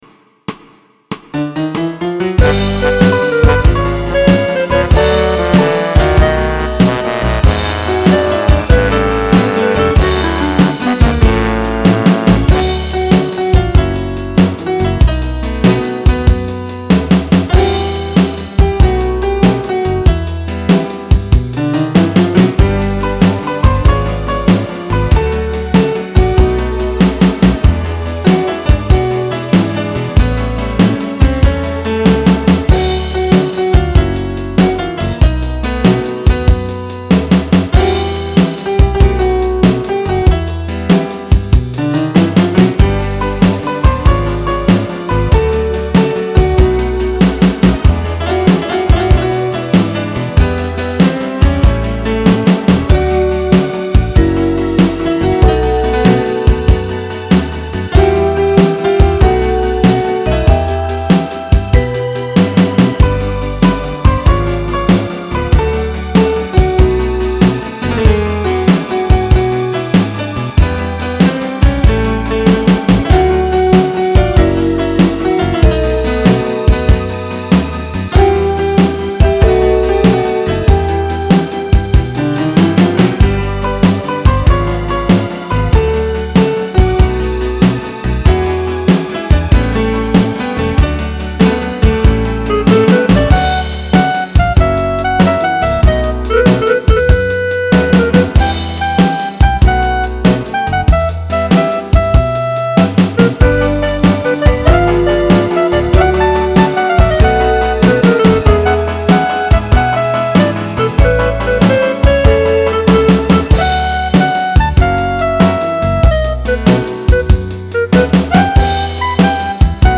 thpcj1u5qg  Download Instrumental